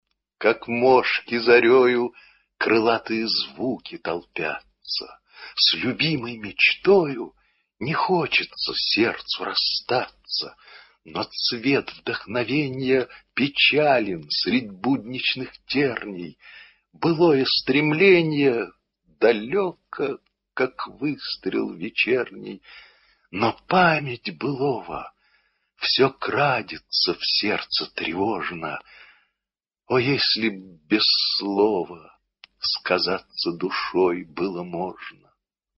1. «Фет Афанасий – Как мошки зарею… (читает Яков Смоленский)» /